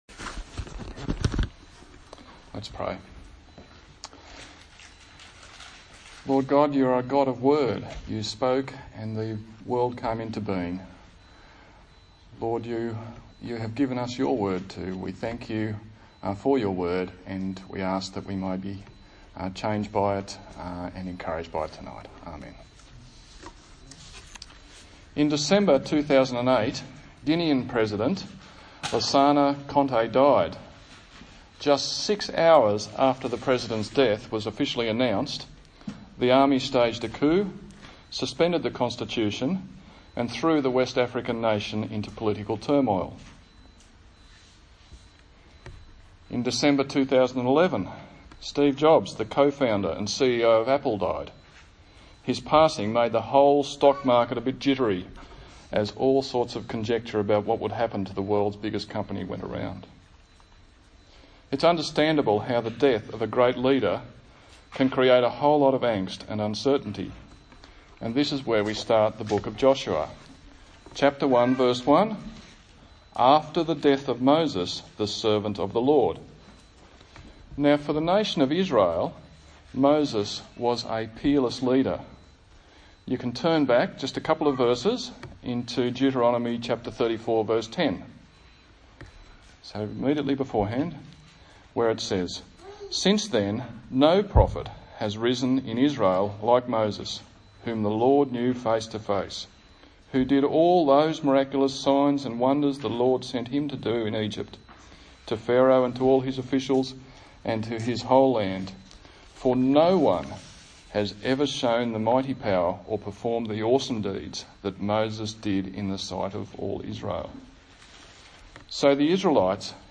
A sermon on the book of Joshua